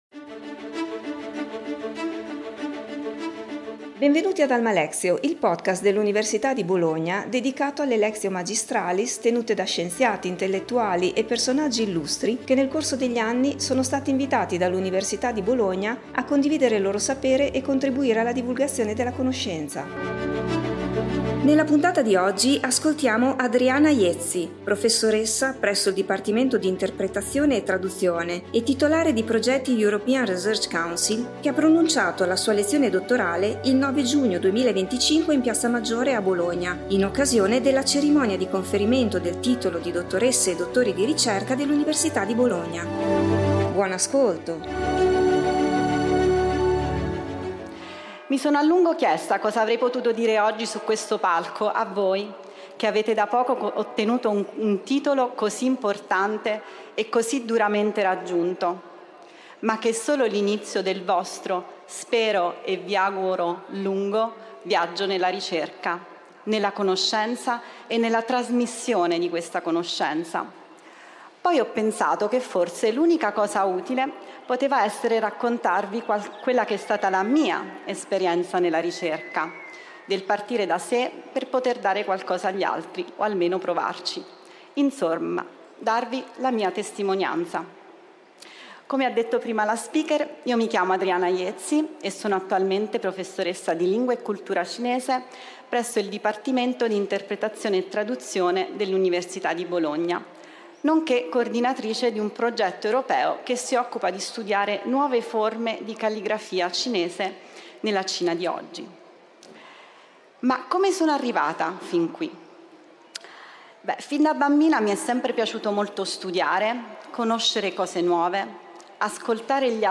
lectio magistralis
in piazza Maggiore a Bologna